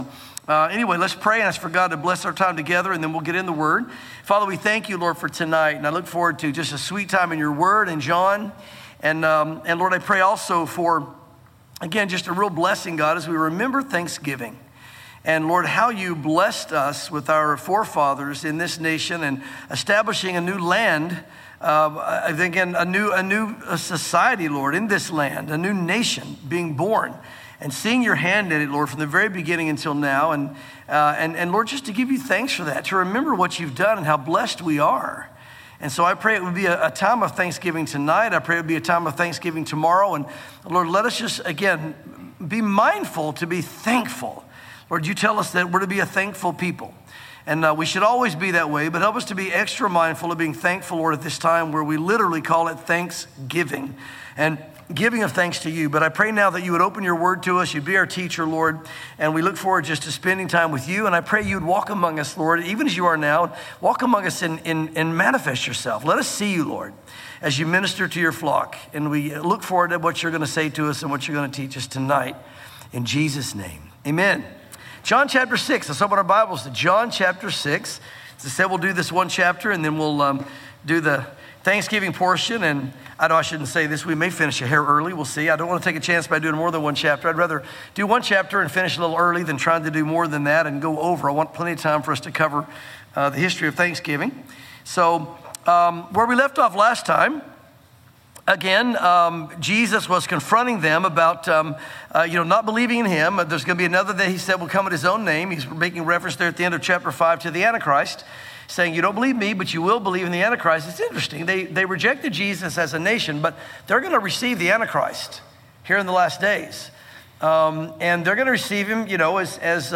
sermons John 6